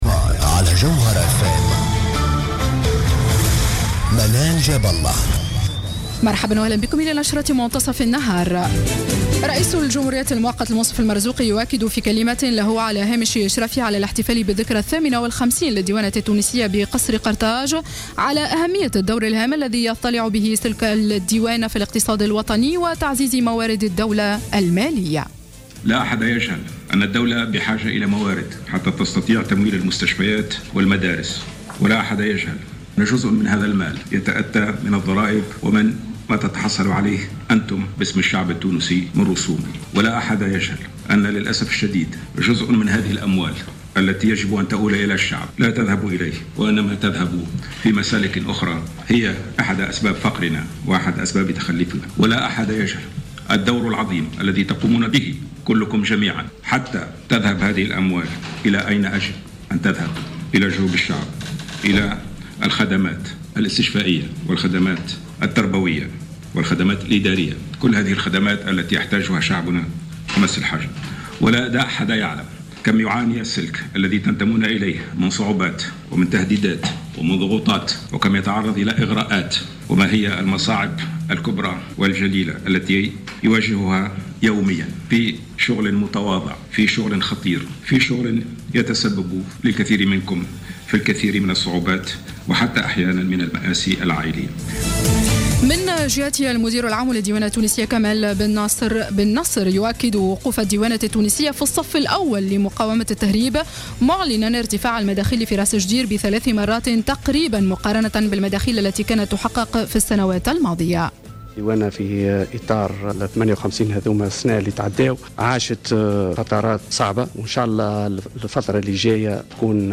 نشرة أخبار منتصف النهار ليوم السبت 06-12-14